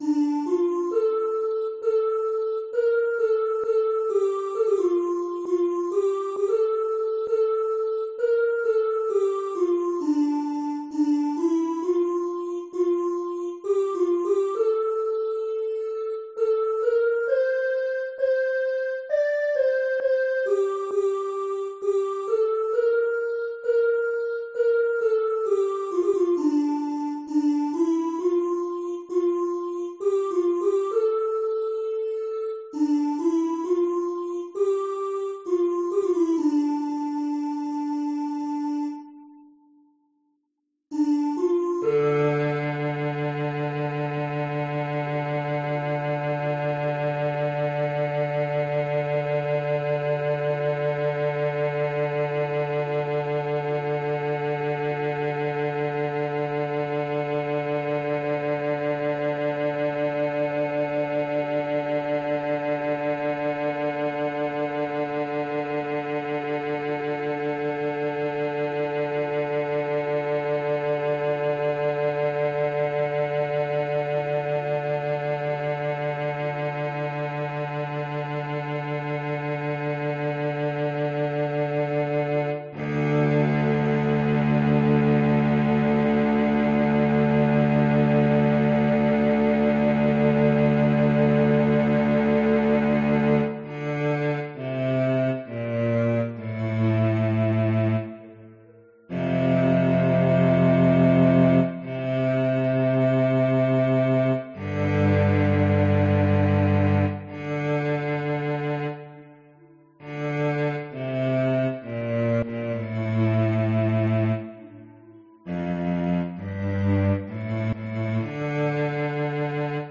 - Hymne du soir en norvégien